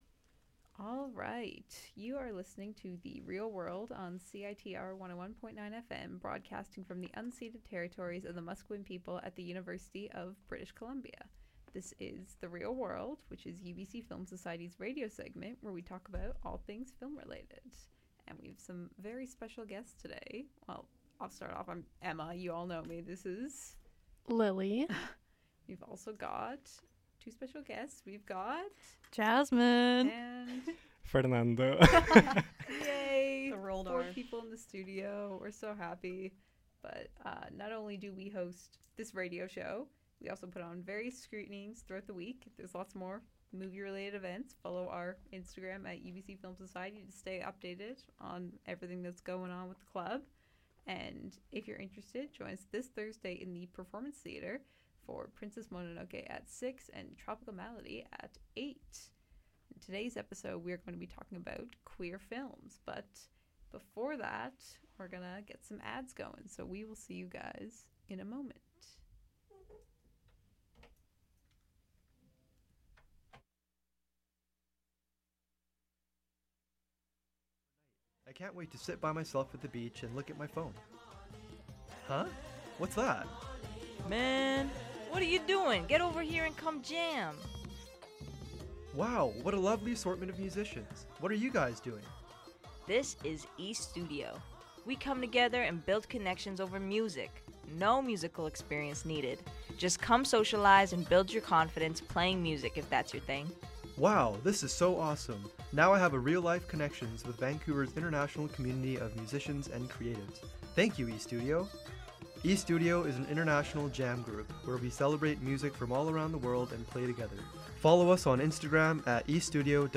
2024 Queer Films 4:00pm